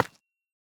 Minecraft Version Minecraft Version 1.21.5 Latest Release | Latest Snapshot 1.21.5 / assets / minecraft / sounds / block / calcite / place2.ogg Compare With Compare With Latest Release | Latest Snapshot